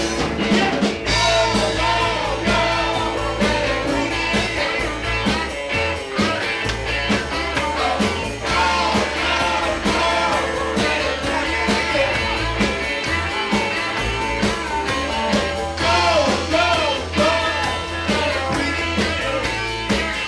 99.６月ミントンハウスでの演奏曲目